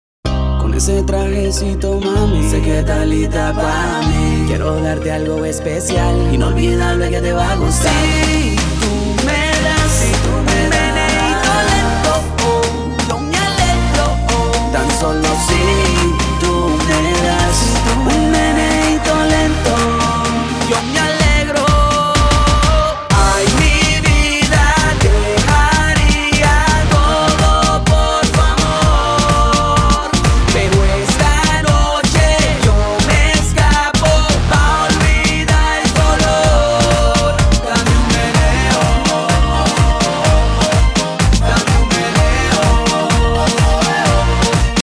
• Качество: 320, Stereo
OST